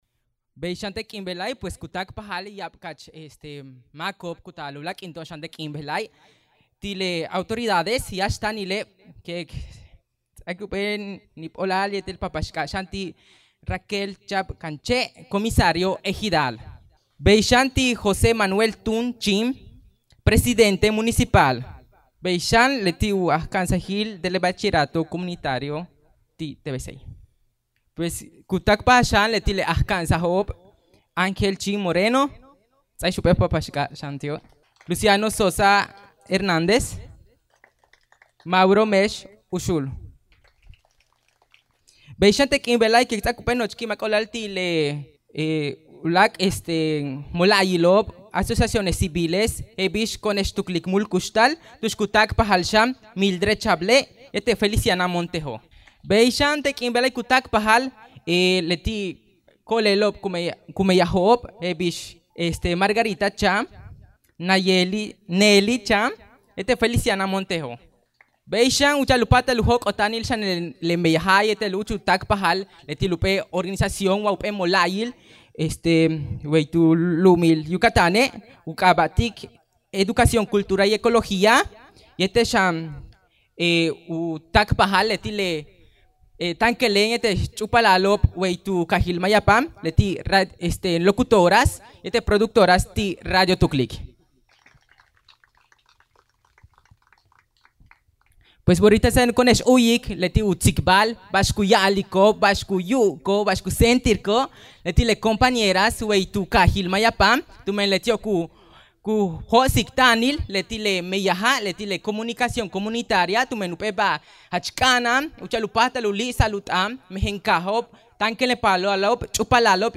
El pasado sábado 6 de diciembre celebramos la inauguración de la nueva cabina de radio comunitaria en Mayapán, Yucatán, un logro construido desde el trabajo colectivo y la participación activa de la comunidad.
inauguracion-cabina-mayapan_01-limpio.mp3